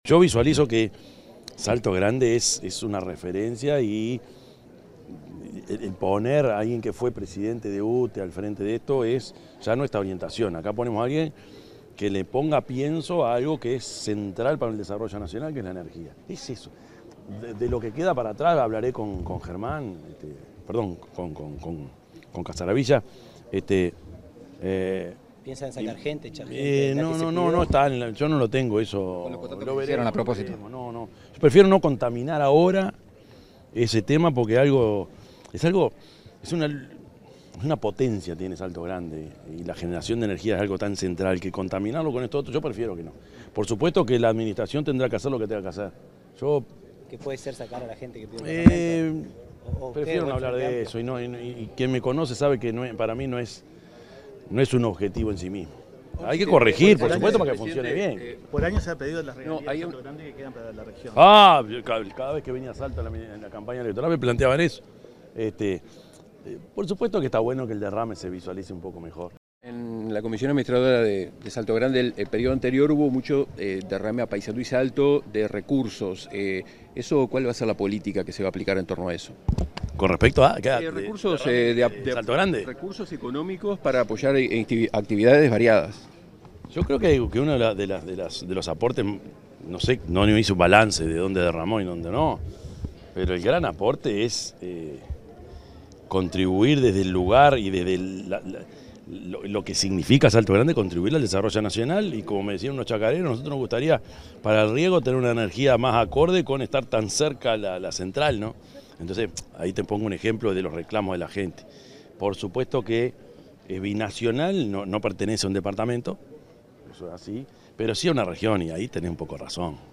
El presidente de la República, profesor Yamandú Orsi, dialogó con la prensa en Salto, acerca de la asunción de las autoridades de la Comisión Técnica